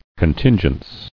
[con·tin·gence]